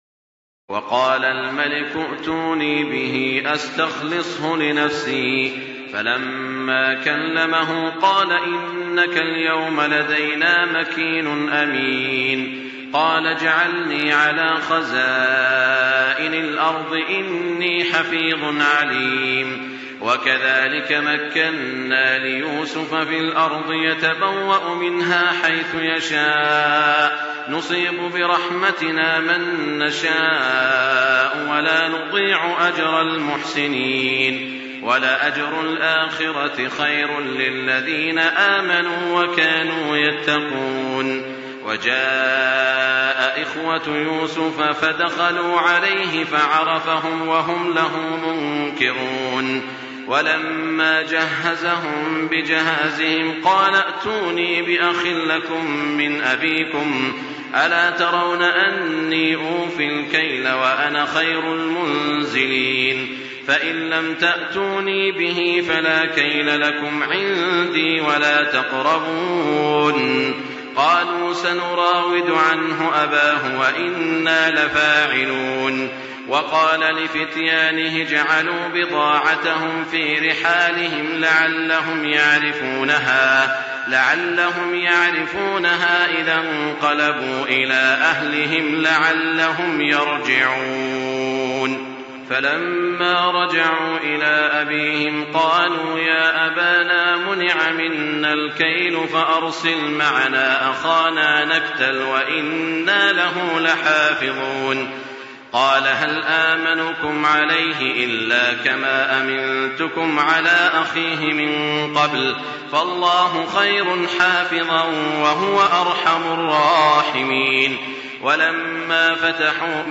تراويح الليلة الثانية عشر رمضان 1424هـ من سورتي يوسف (54-111) و الرعد (1-18) Taraweeh 12 st night Ramadan 1424H from Surah Yusuf and Ar-Ra'd > تراويح الحرم المكي عام 1424 🕋 > التراويح - تلاوات الحرمين